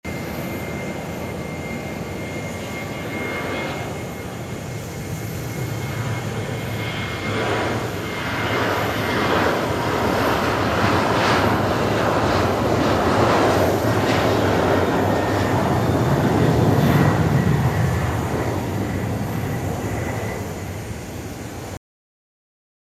今回は、神戸空港で飛行機の離陸音を撮影しました。
離陸音
画像加工と音声加工しました。
撮影は展望デッキのフェンスからスマホだけを乗り出させて撮影しました。
TASCAM(タスカム) DR-07Xのステレオオーディオレコーダー使用しています。